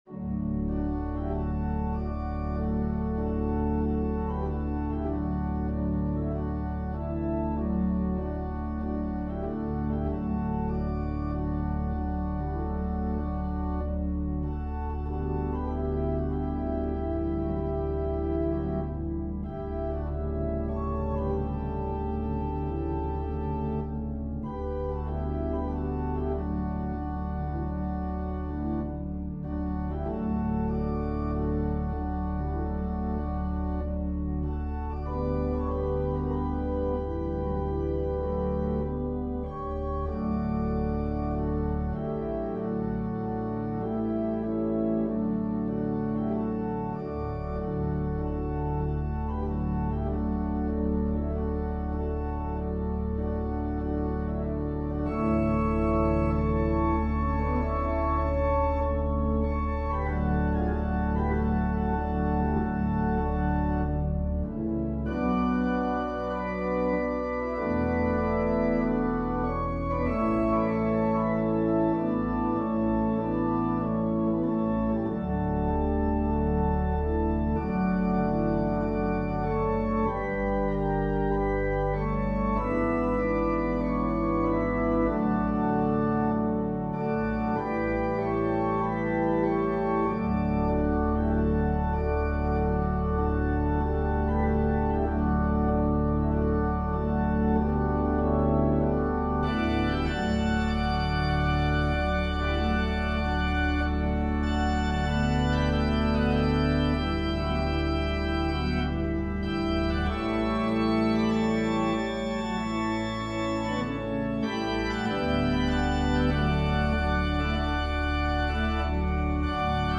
Organ solo version
Voicing/Instrumentation: Organ/Organ Accompaniment